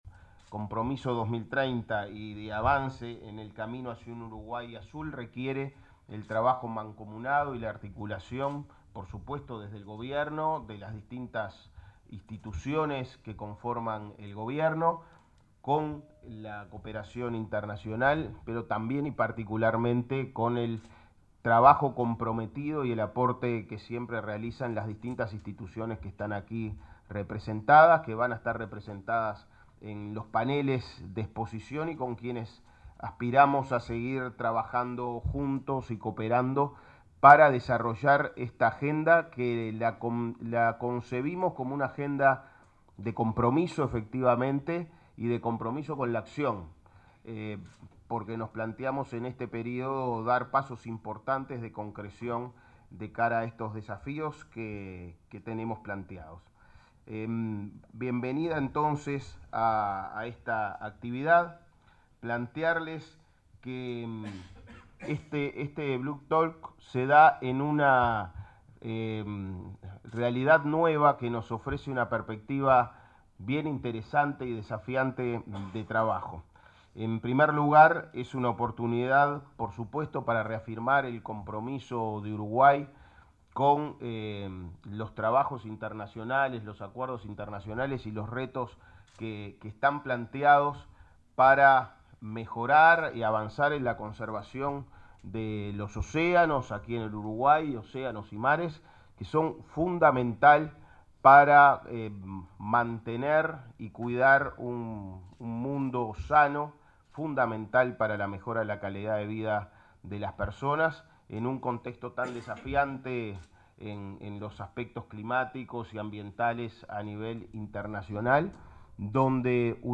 Palabras del ministro de Ambiente, Edgardo Ortuño
El ministro de Ambiente, Edgardo Ortuño, participó de la segunda edición del Blue Talk Montevideo.